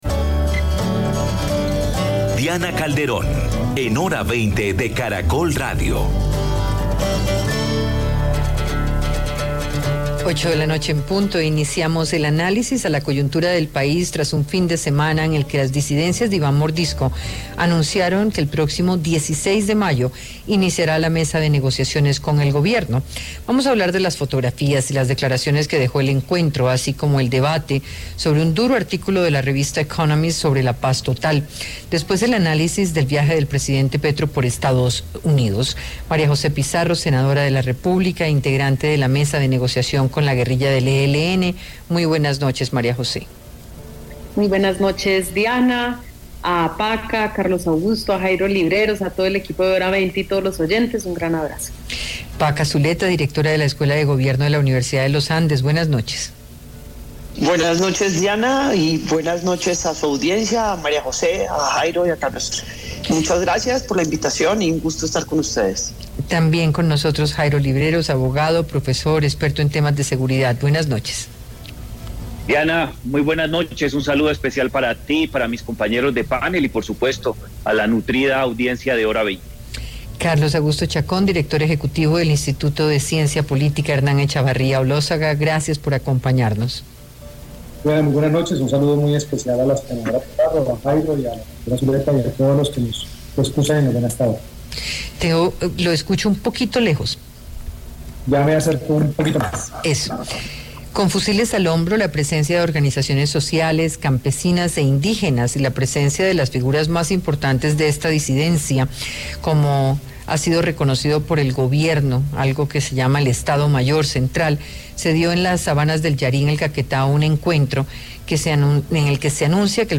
Escúchenos en Caracol Radio a partir de las 7:50 p.m.
Panelistas consideran que las imágenes que dejó el encuentro en el Yarí evidencian que la guerrilla quería mostrar su poder y capacidad de control territorial